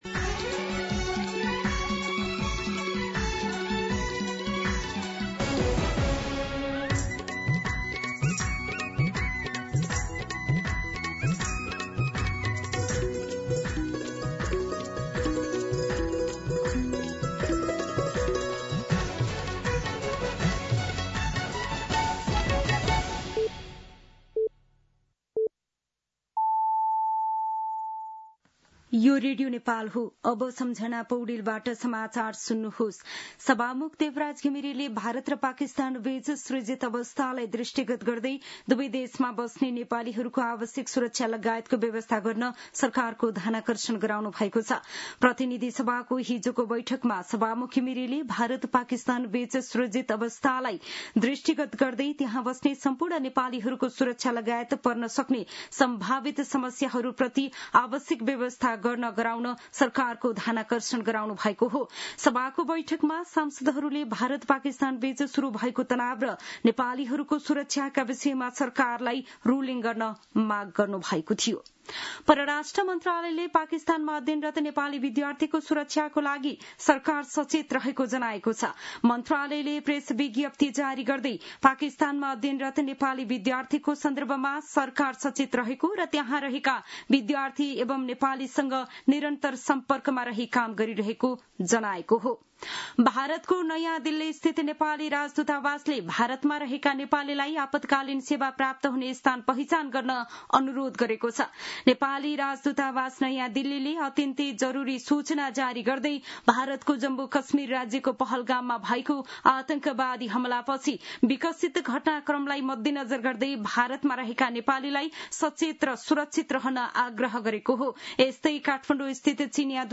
दिउँसो १ बजेको नेपाली समाचार : २७ वैशाख , २०८२